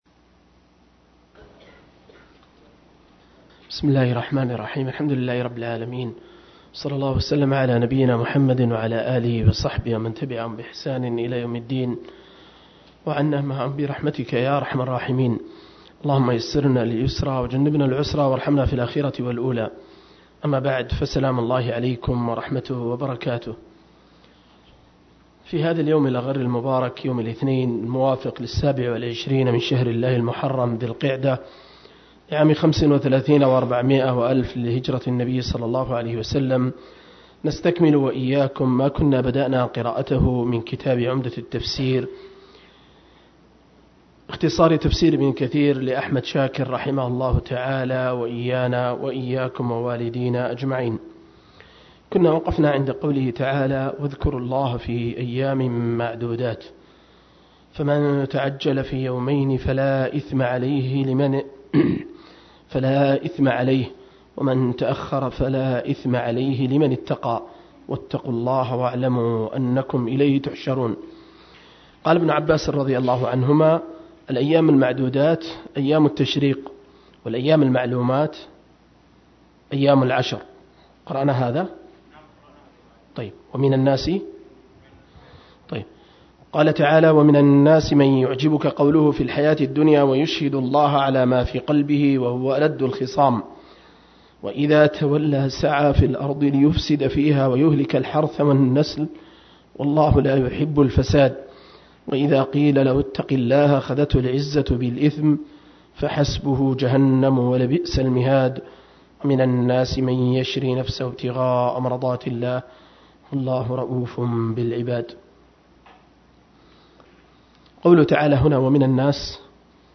042- عمدة التفسير عن الحافظ ابن كثير – قراءة وتعليق – تفسير سورة البقرة (الآيات 214-204)